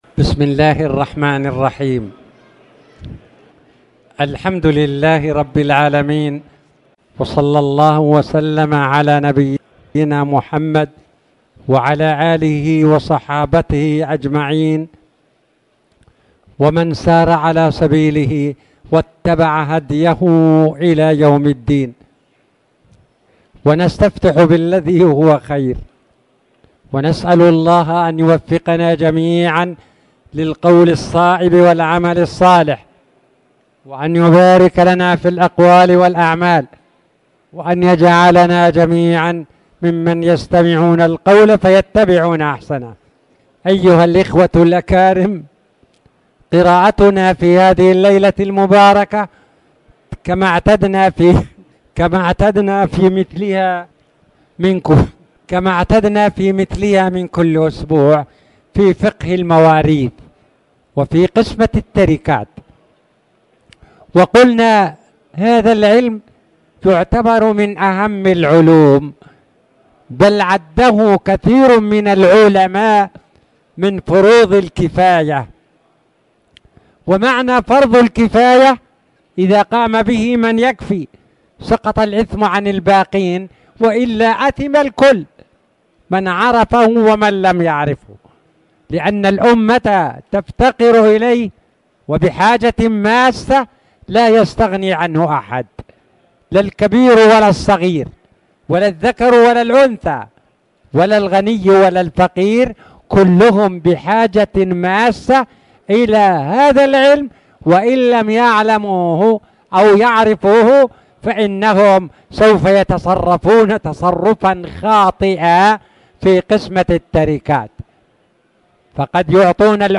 تاريخ النشر ٢١ جمادى الآخرة ١٤٣٨ هـ المكان: المسجد الحرام الشيخ